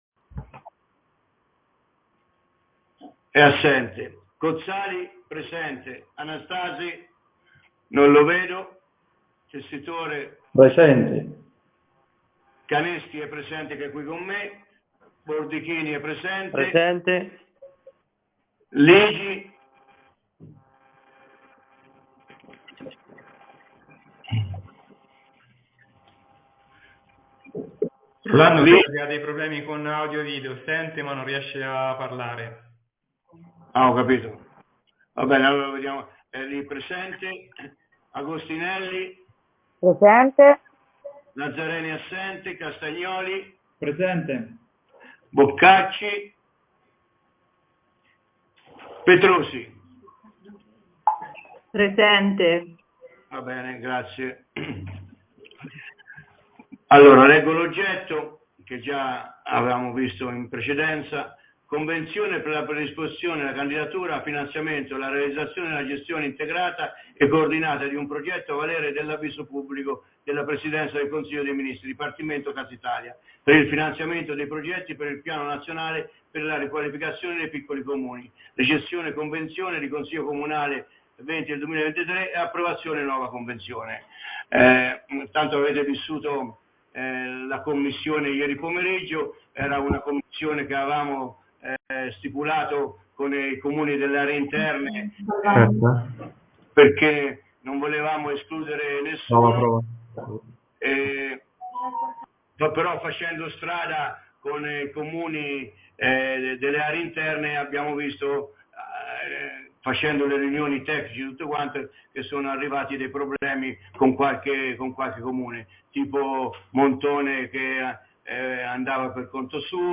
Consiglio Comunale del 18 Ottobre 2023